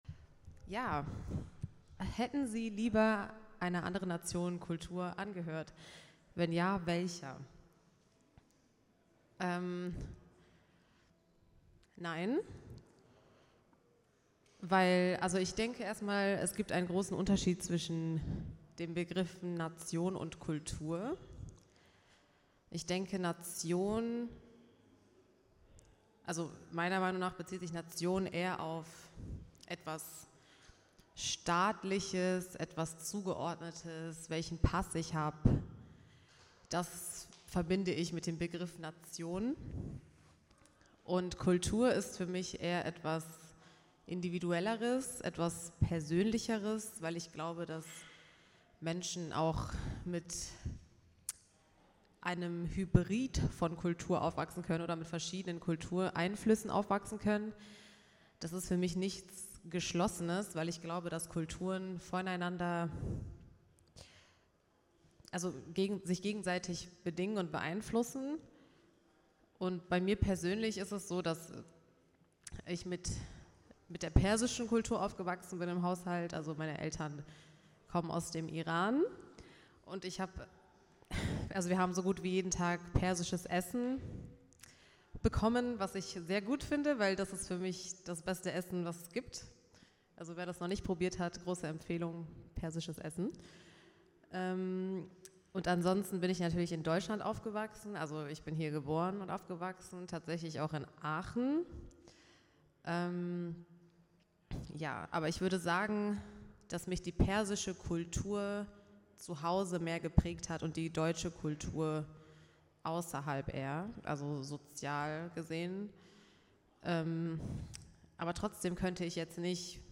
Performance und Installation   180 Minuten
Kunsthaus NRW Kornelimünster